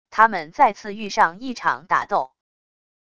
他们再次遇上一场打斗wav音频生成系统WAV Audio Player